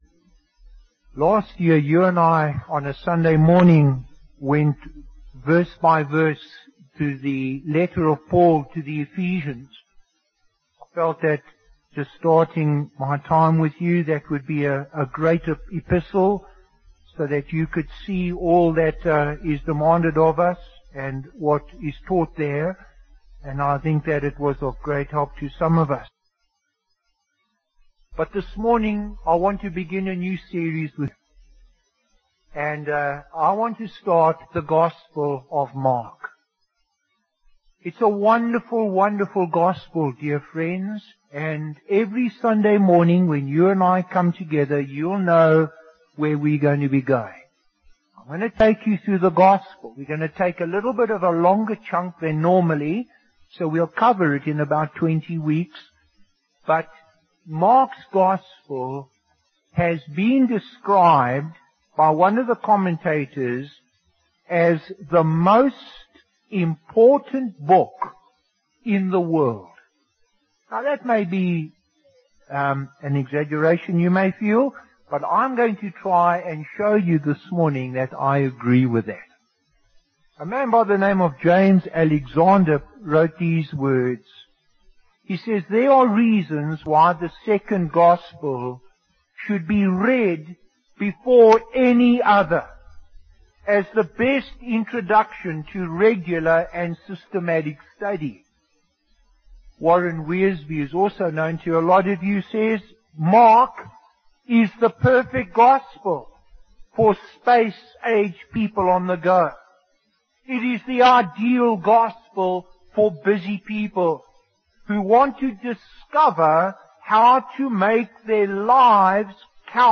Preacher: Bishop Warwick Cole-Edwards | Series: Mark